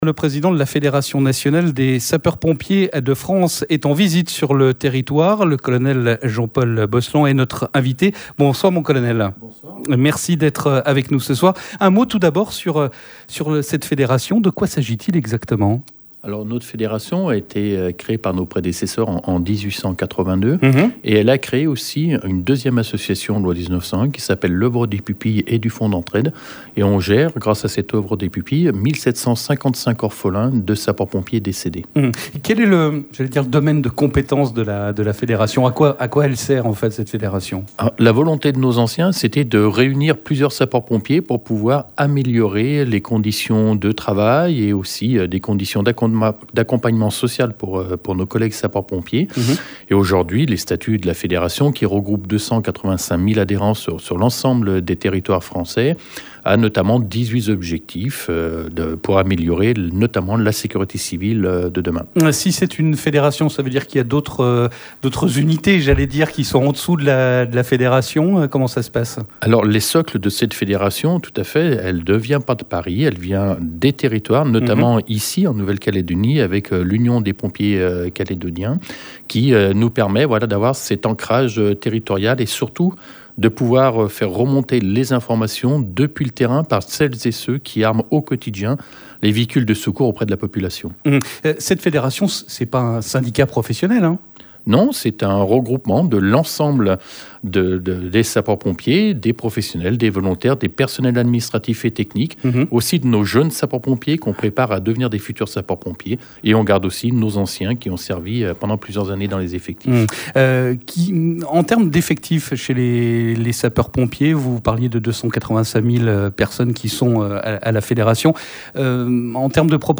L'invité du journal